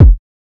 Kick (Tesla).wav